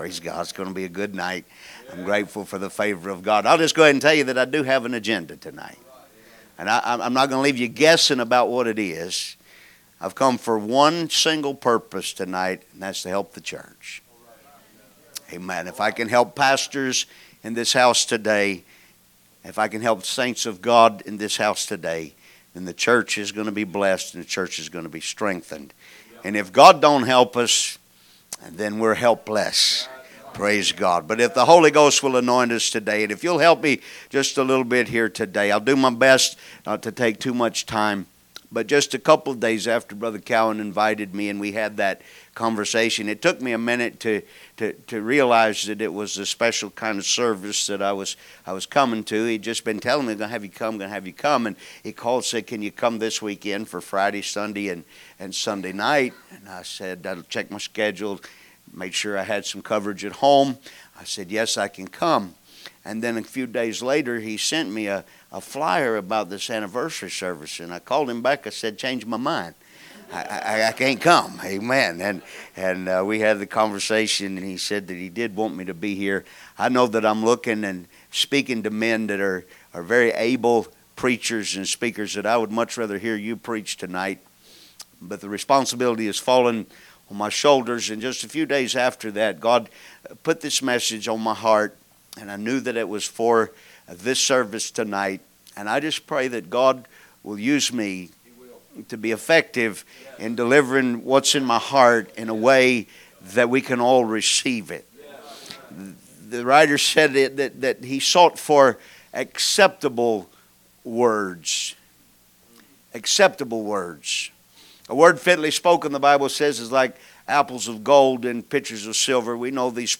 Friday Message - 5-year Anniversary